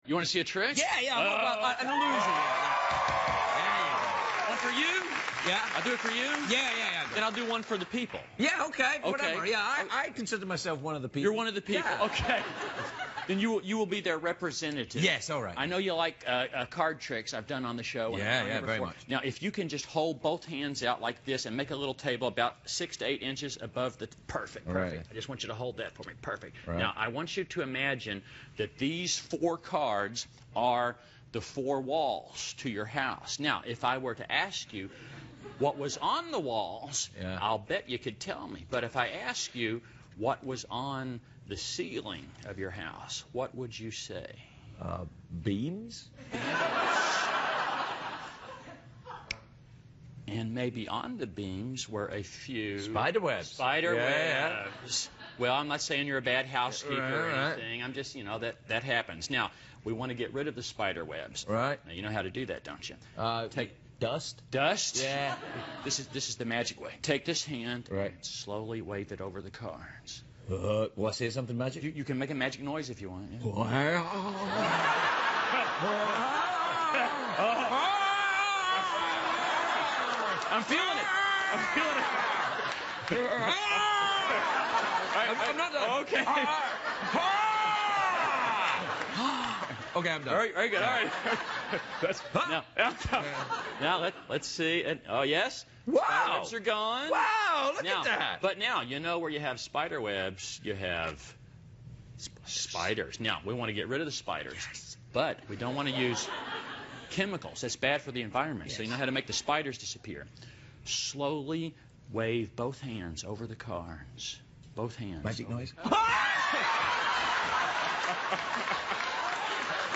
访谈录 2011-06-03&06-05 魔术师兰斯·伯顿专访 听力文件下载—在线英语听力室